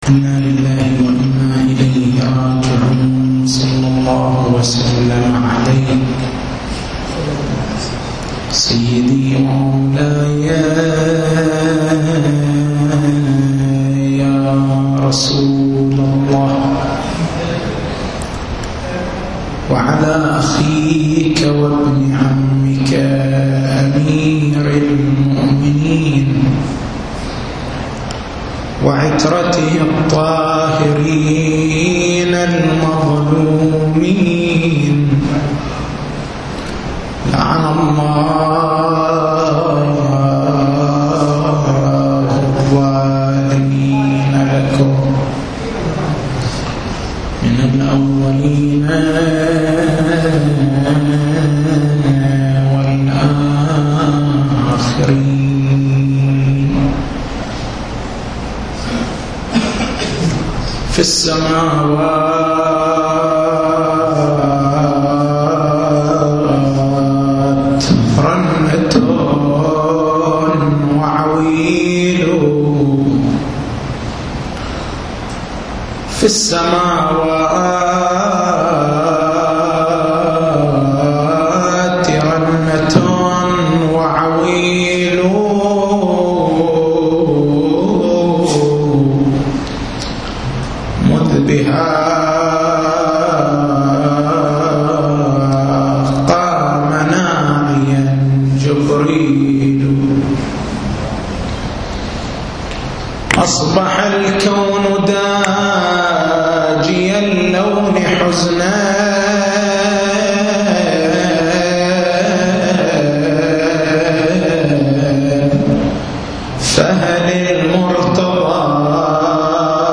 تاريخ المحاضرة: 22/09/1430 نقاط البحث: ما هو الفرق بين التوسل والاستغاثة؟ لماذا نتوسل بأمير المؤمنين (ع)؟